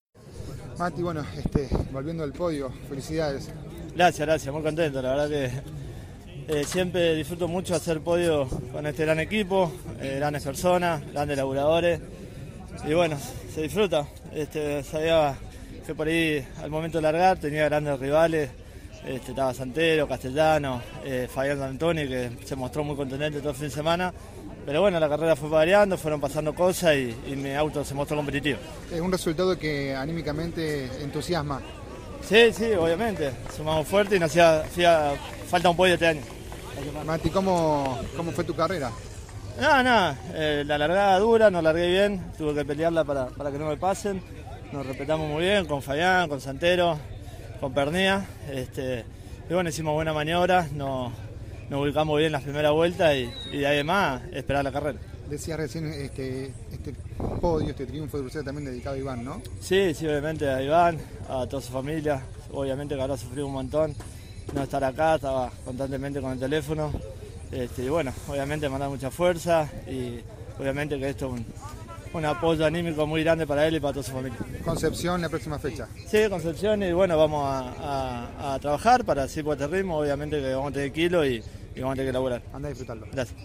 Las actividades de la sexta fecha de la temporada del Turismo Nacional en Posdas, Misiones, se cerró con la final de la Clase 3 que, tras ella, CÓRDOBA COMPETICIÓN dialogó con los protagonistas.